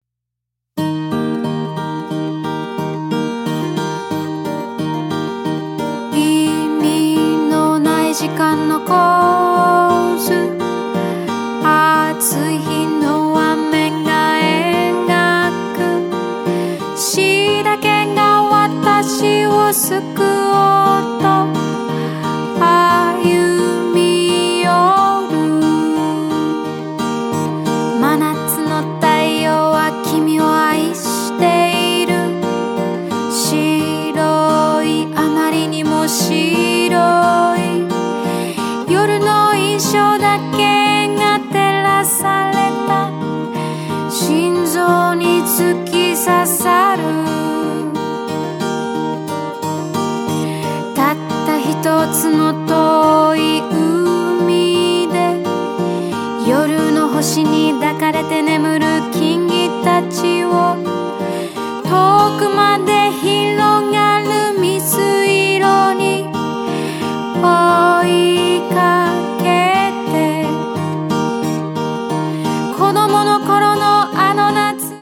かなりプログレッブな感覚が横溢した作品となっていますね！
ロックのエッセンスがかなり出ていて
濃厚な演奏を楽しめます！